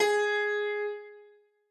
Harpsicord